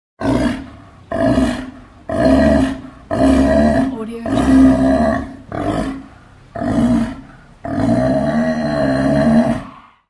Sea Lion Efeito Sonoro: Soundboard Botão
Sea Lion Botão de Som